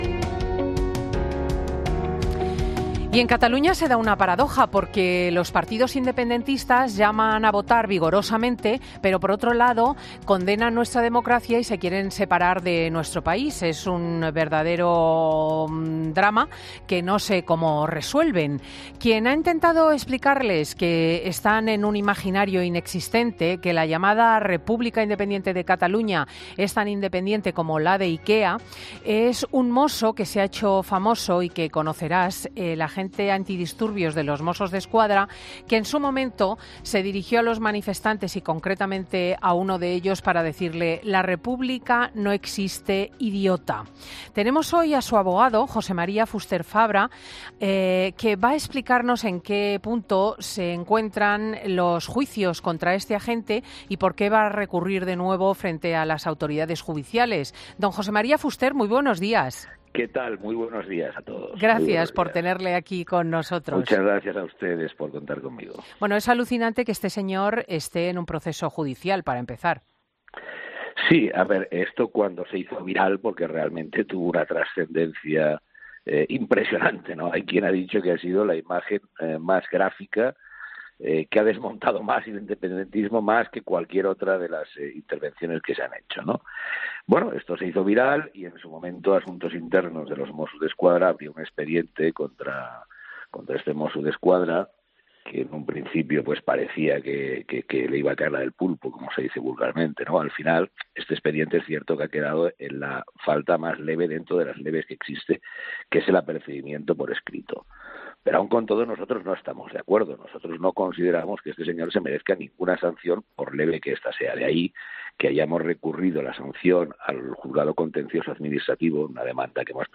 Este domingo ha sido entrevistado en 'Fin de Semana', donde ha dicho que cuando se hizo viral la imagen Asuntos Internos de los Mossos abrió un expediente contra su representado.